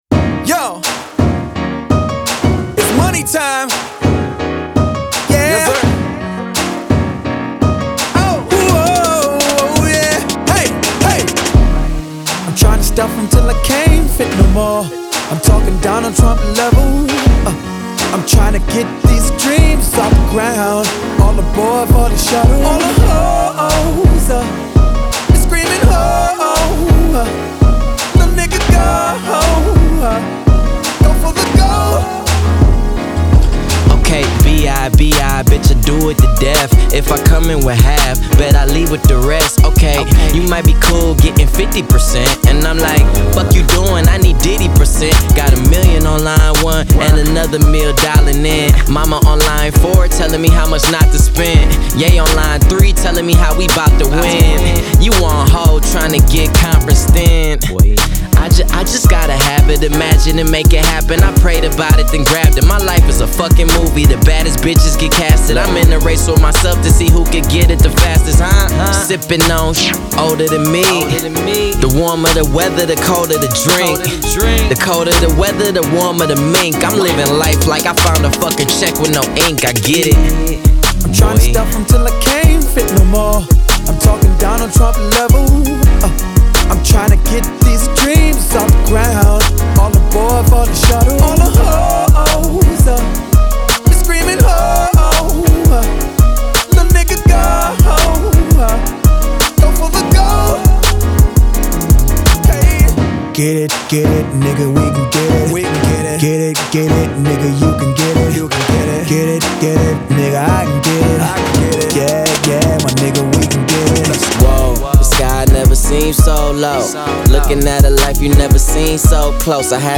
Genre : Rap, Hip Hop